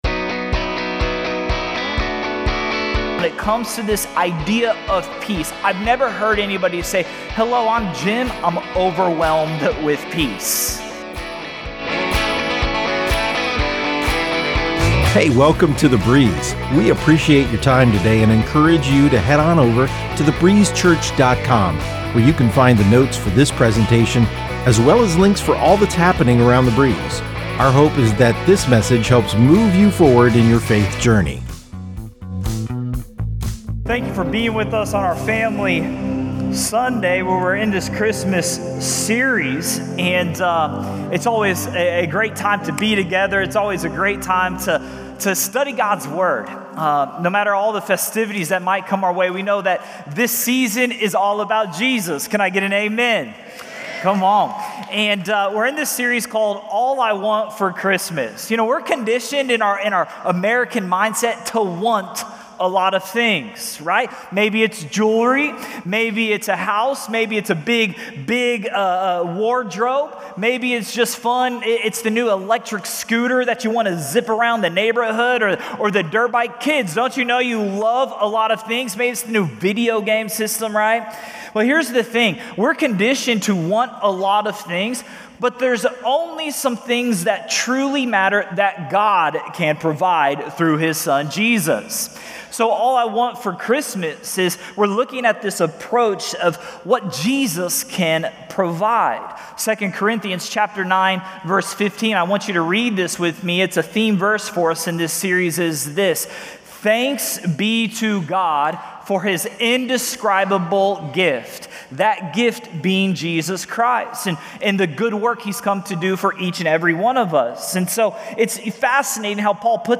1 Sunday 9.22.24 Sermon 38:46